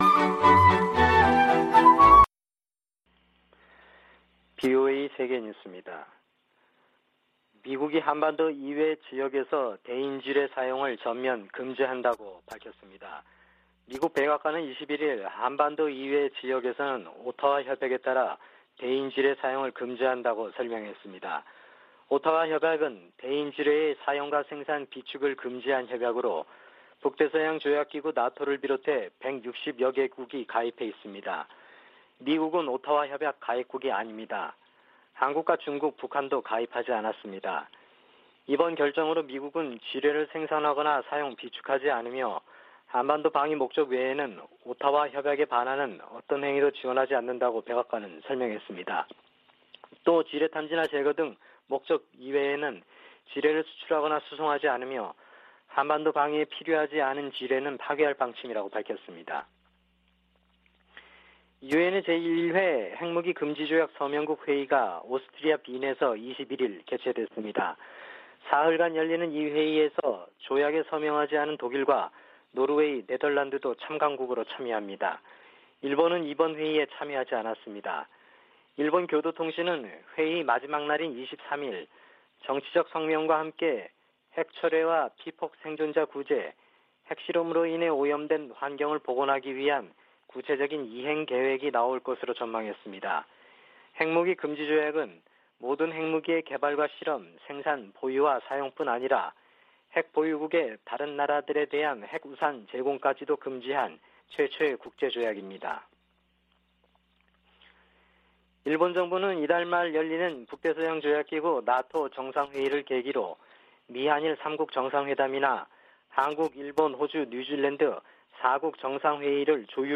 VOA 한국어 아침 뉴스 프로그램 '워싱턴 뉴스 광장' 2022년 6월 22일 방송입니다. 한국이 21일 자체 개발 위성을 쏘아올리는데 성공해, 세계 7번째 실용급 위성 발사국이 됐습니다. 북한이 풍계리 핵실험장 4번 갱도에서 핵실험을 하기 위해서는 수개월 걸릴 것이라고 미국 핵 전문가가 전망했습니다. 북한이 과거 기관총과 박격포 탄약, 수류탄 등 약 4천만개의 탄약을 중동 국가에 판매하려던 정황이 확인됐습니다.